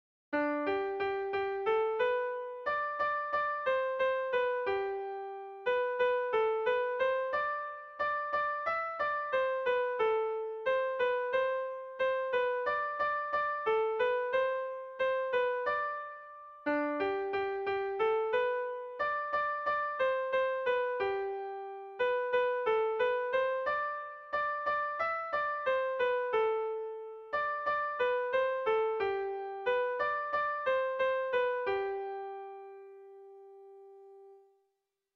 Bertso melodies - View details   To know more about this section
Haurrentzakoa
ABDABE